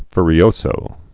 (fyrē-ōsō, -zō)